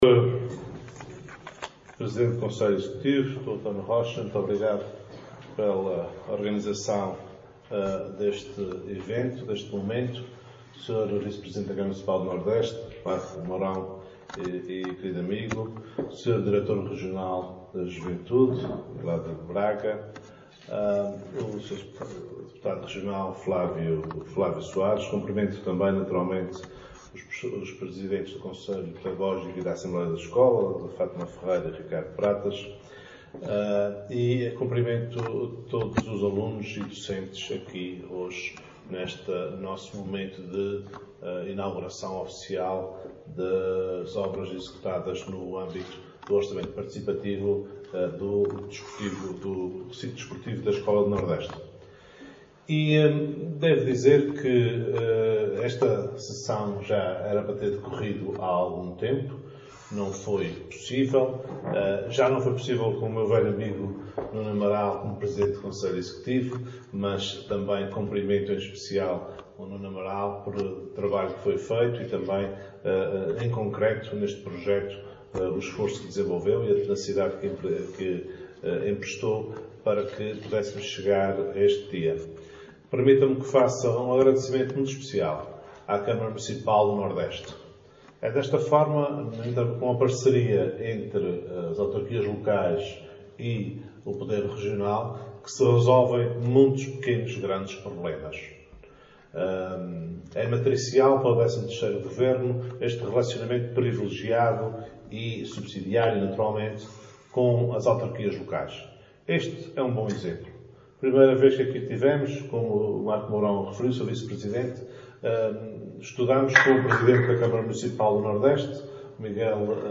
Duarte Freitas falava durante a inauguração das obras de requalificação do campo de jogos da Escola Básica e Secundária do Nordeste, em que esteve acompanhado pelo D